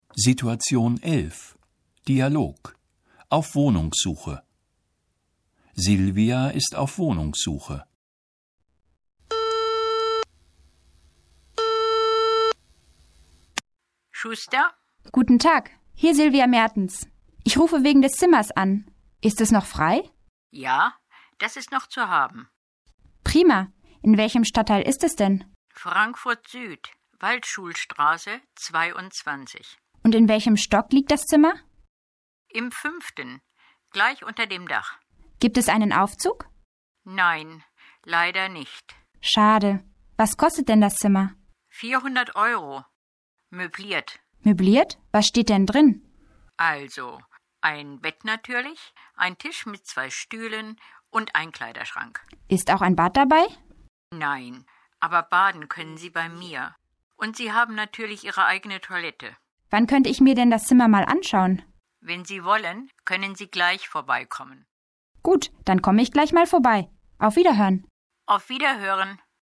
Situation 11 – Dialog: Auf Wohnungssuche (1111.0K)